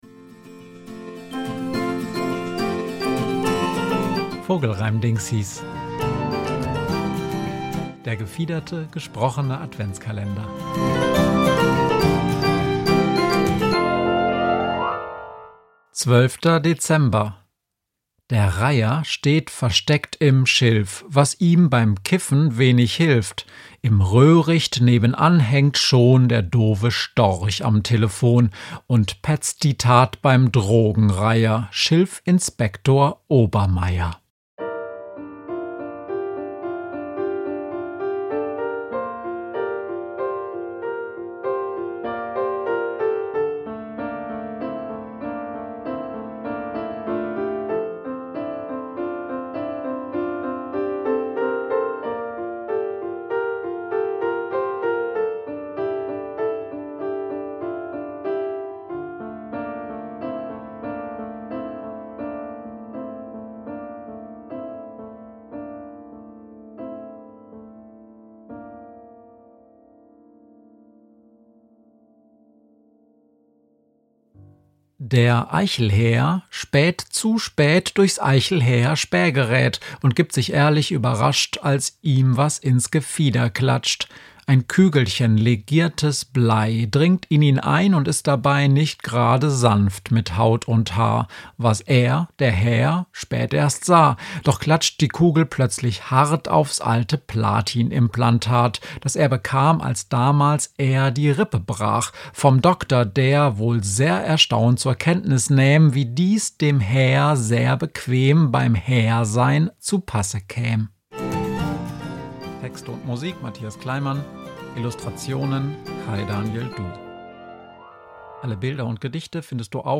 gefiederte, gesprochene Adventskalender